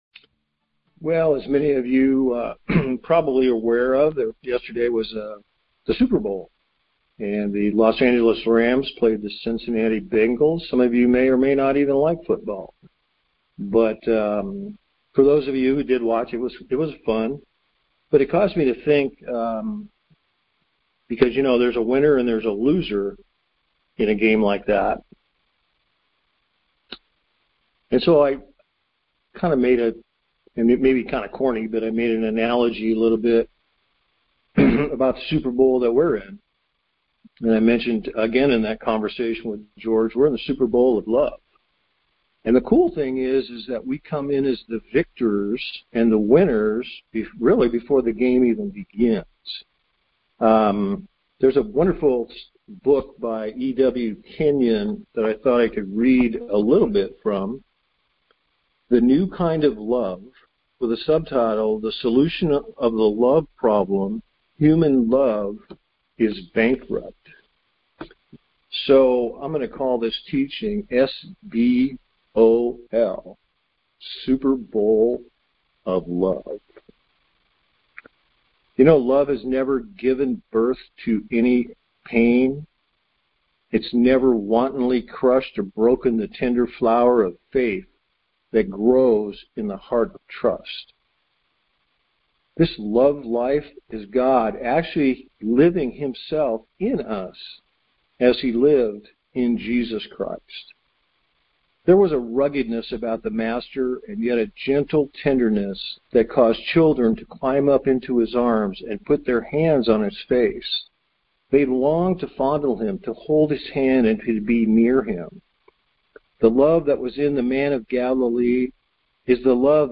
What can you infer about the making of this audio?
Super Bowl of Love Details Series: Conference Call Fellowship Date: Sunday, 13 February 2022 Hits: 533 Play the sermon Download Audio ( 3.90 MB )